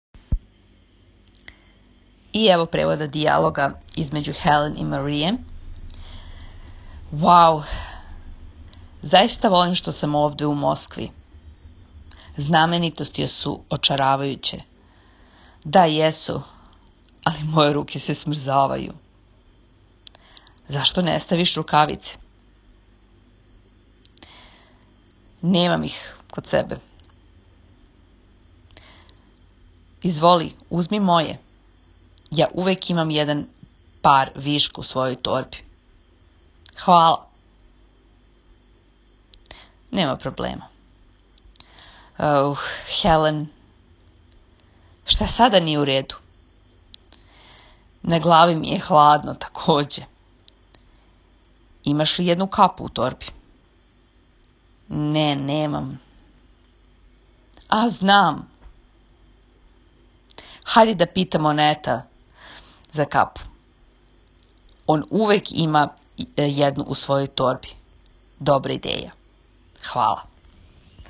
5.раз.-дијалог81.-страна-превод.m4a